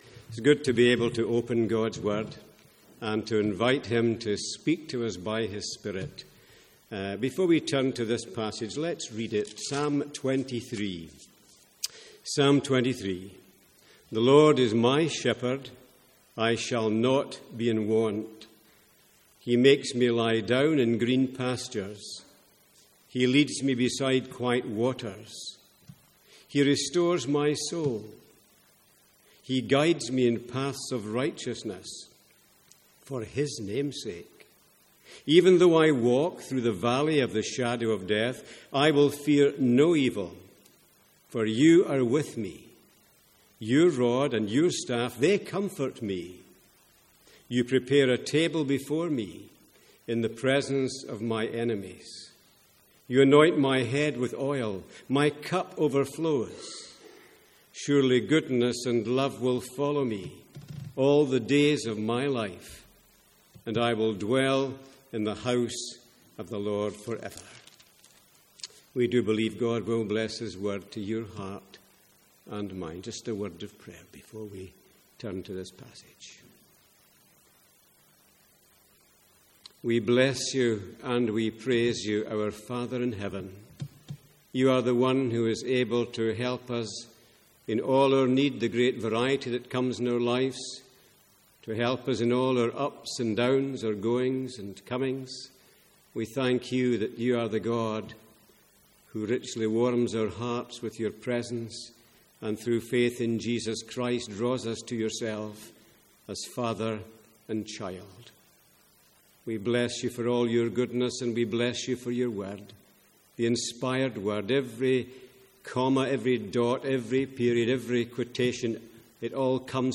Sermons | St Andrews Free Church
Download Download From the Sunday evening series in the Psalms.